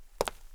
concretFootstep03.wav